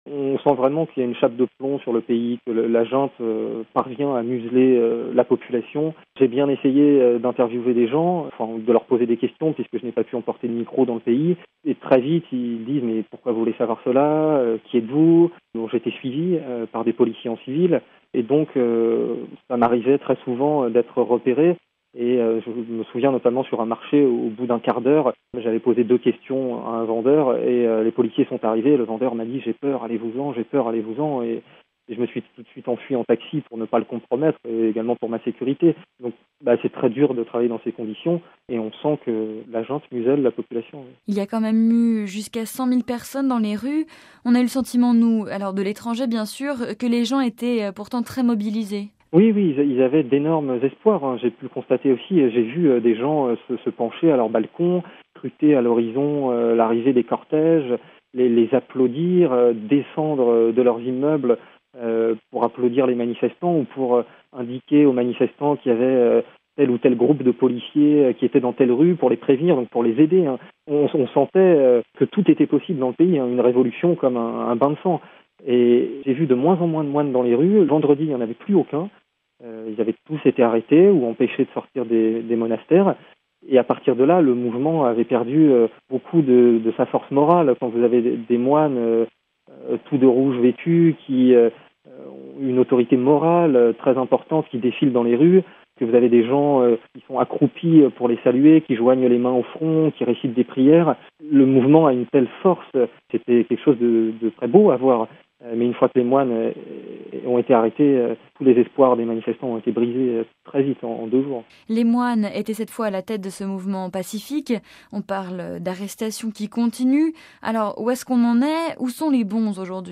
un journaliste francophone qui vient de rentrer de Birmanie RealAudio
Propos recueillis par